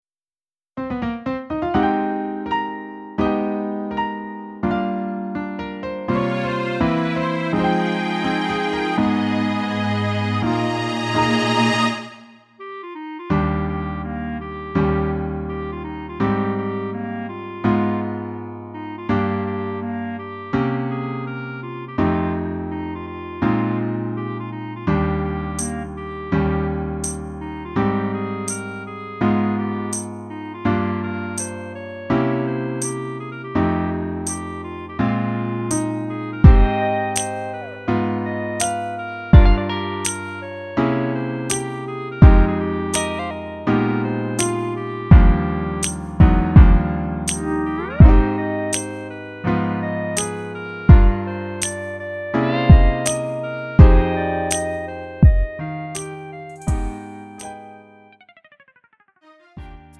음정 원키 3:30
장르 가요 구분